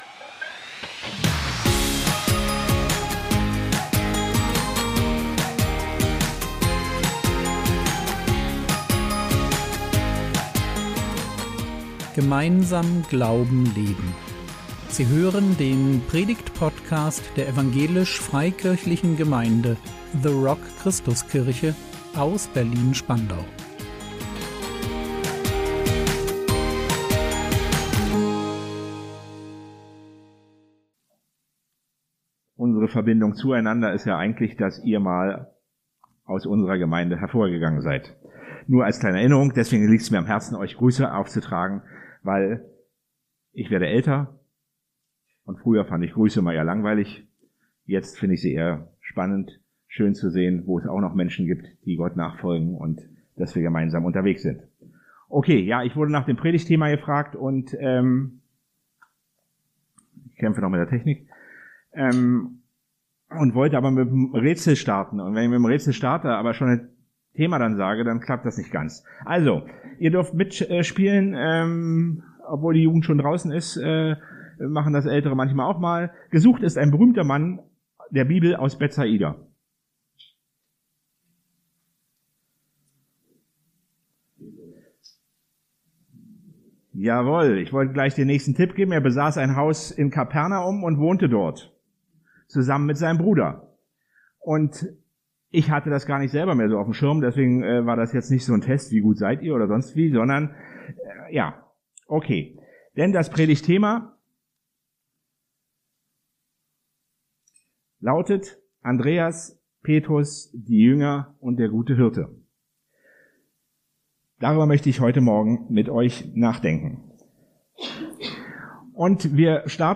Gott macht Unterschiede, aber alle sind geliebt! | 03.11.2024 ~ Predigt Podcast der EFG The Rock Christuskirche Berlin Podcast